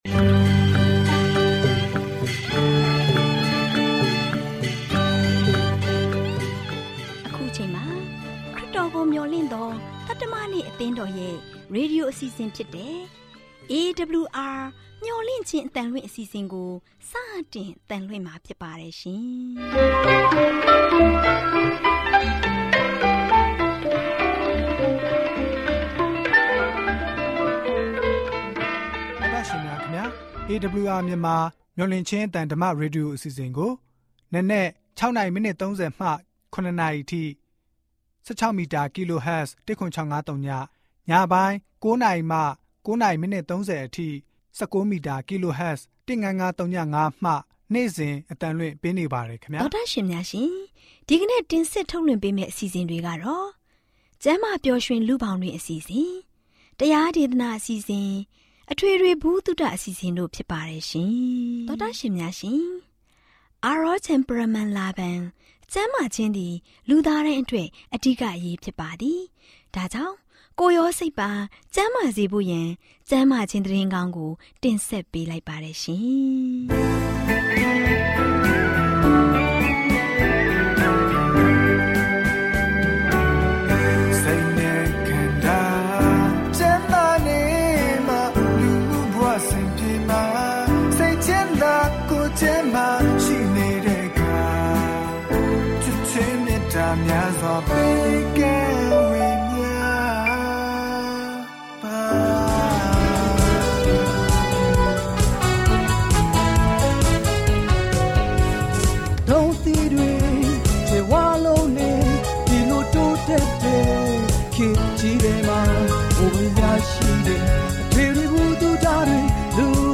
Burmese / မြန်မာစကား radio program for Myanmar provided by Adventist World Radio